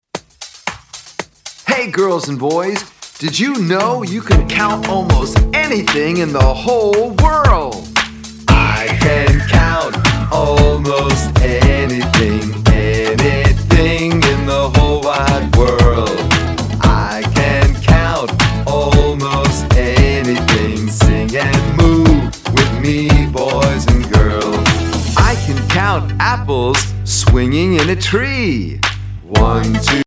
Educational Songs by Subject